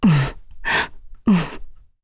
Seria-Vox_Sad.wav